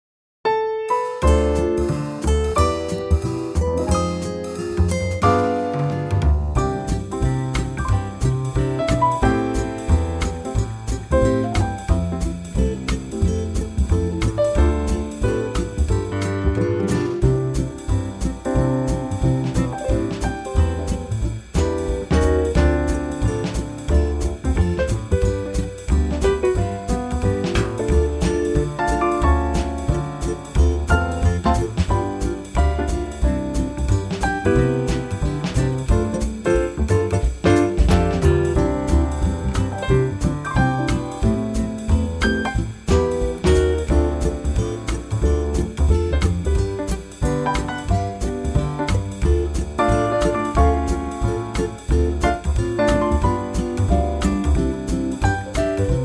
key - C - vocal range - D to Eb
Great swinging Quartet arrangement